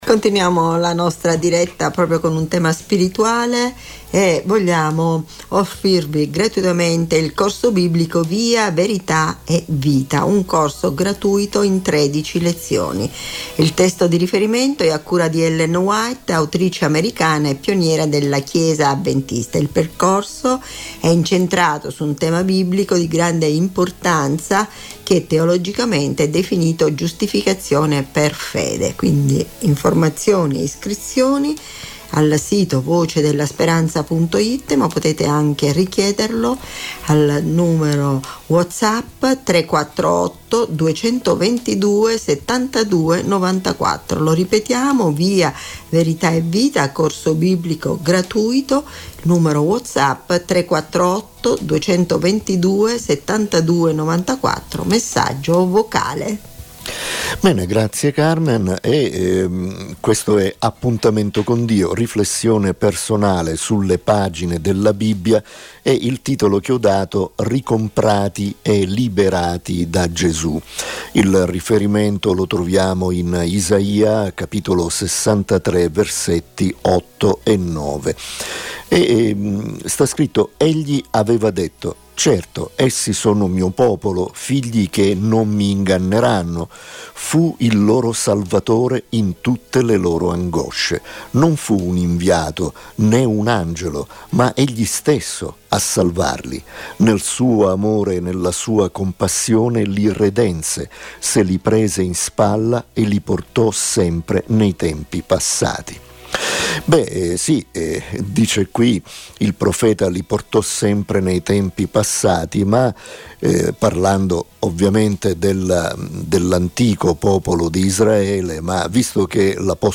Riflessione spirituale